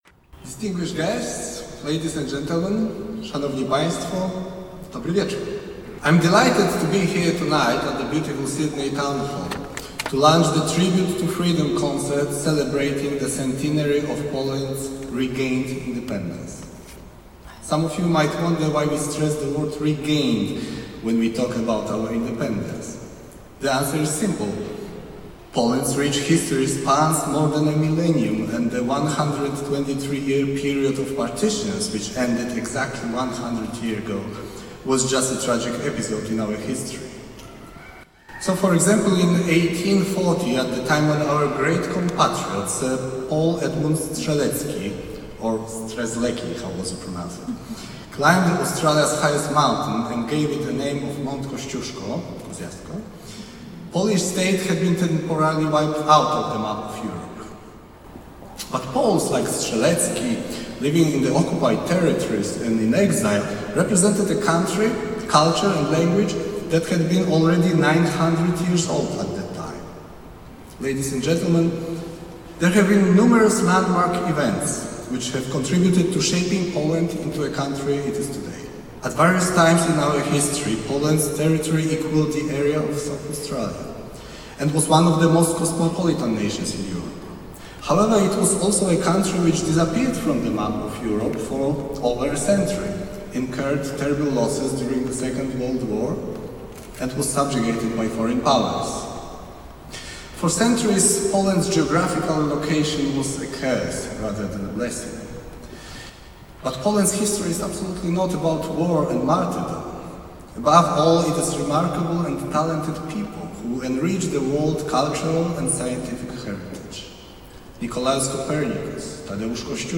Tribute to Freedom w sydnejskim Ratuszu
Przemówienie ambasadora Michała Kołodziejskiego w jęz. angielskim.
AmbasadorKolodziejskiSpeech.mp3